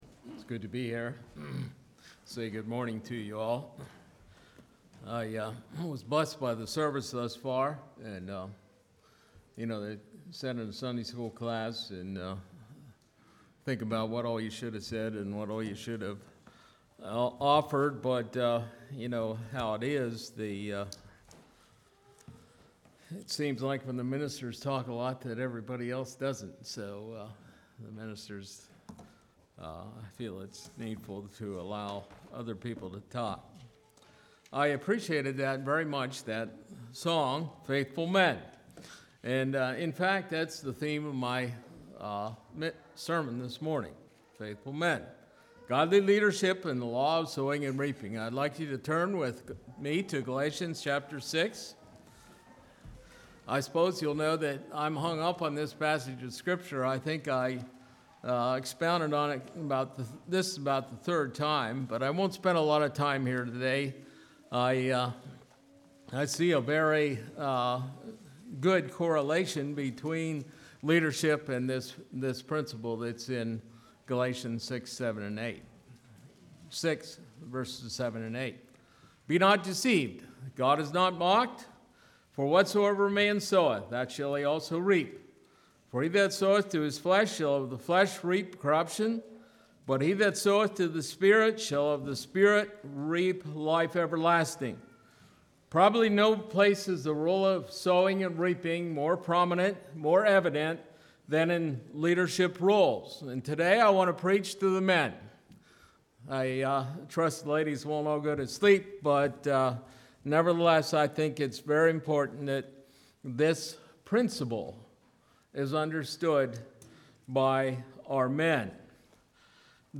2018 Sermon ID